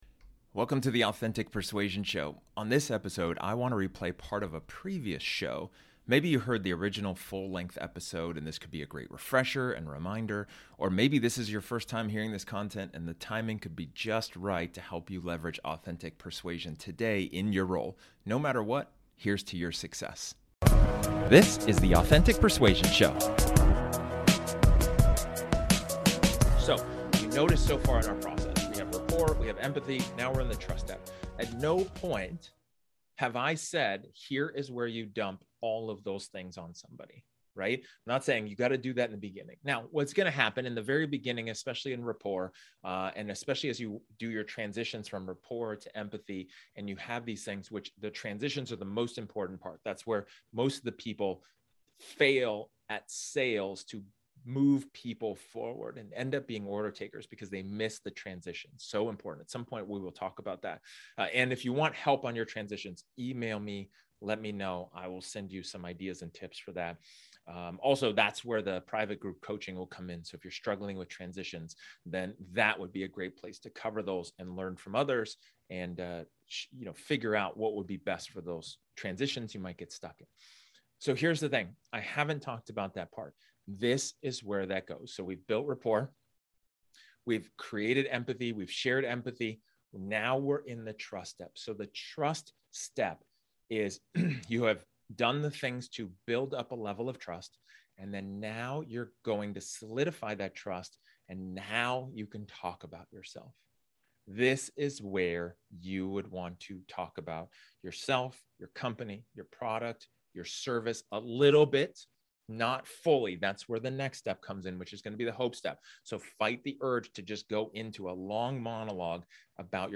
This episode is an excerpt from one of my training sessions where I talk about the formula of trust.